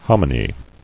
[hom·i·ny]